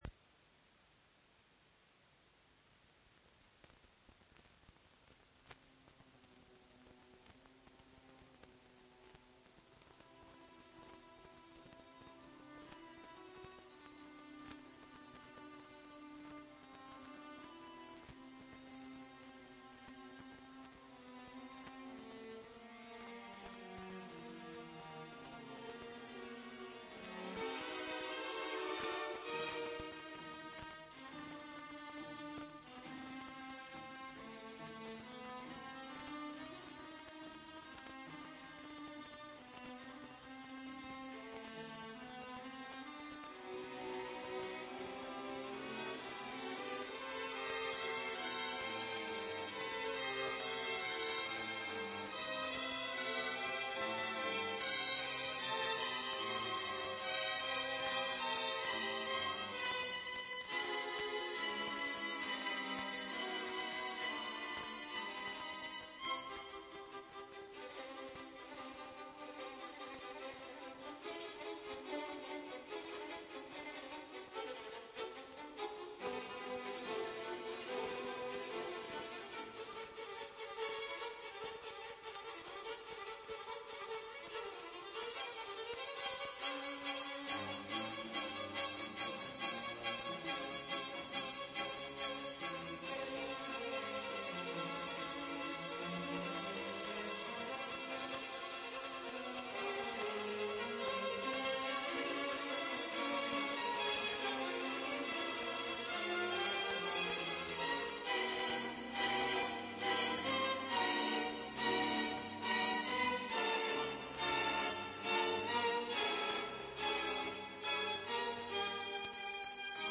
kamera orkestri üçün